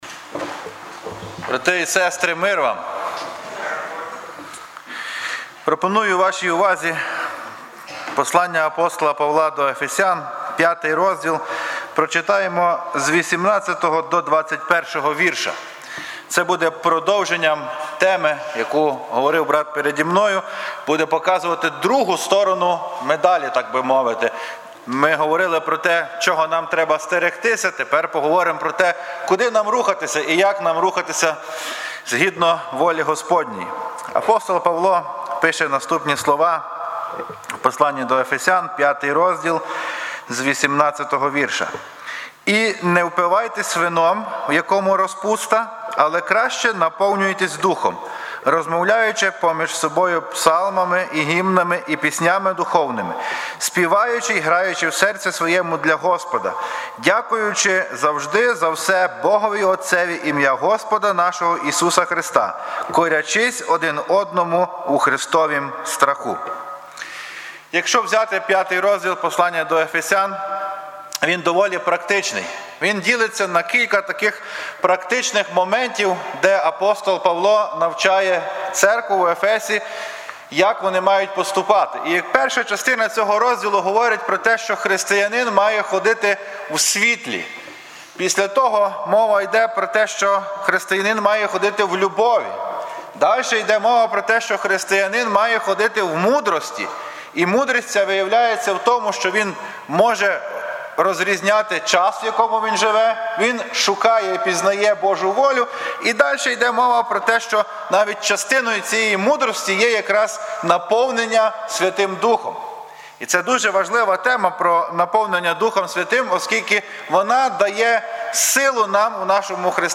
Трускавець Церква Преображення
тема проповіді